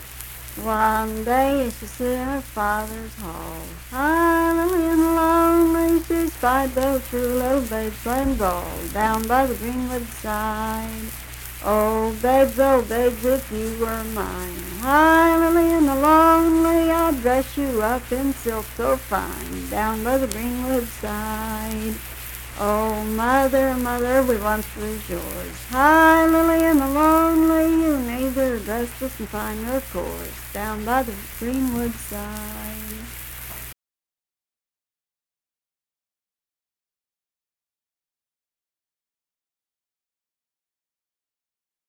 Unaccompanied vocal music
Verse-refrain 3(4w/R).
Voice (sung)
Sutton (W. Va.), Braxton County (W. Va.)